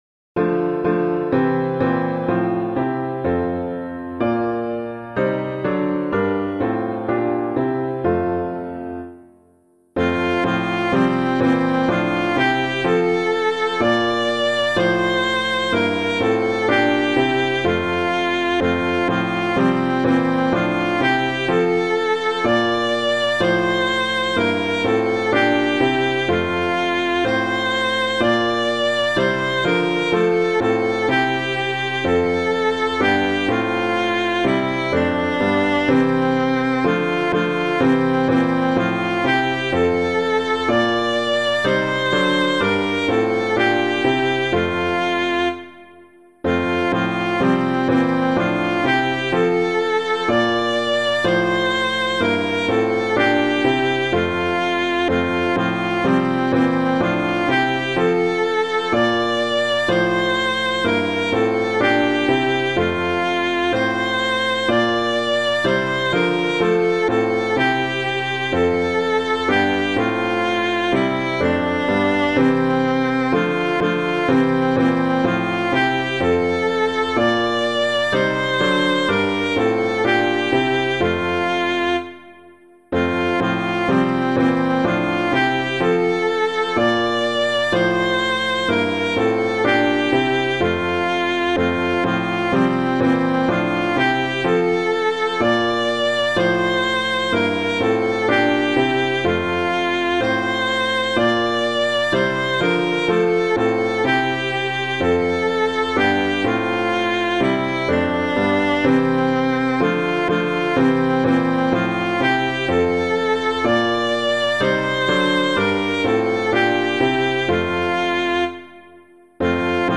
Hymn suitable for Catholic liturgy.
When the King Shall Come Again [Idle - AVE VIRGO VIRGINUM] - piano.mp3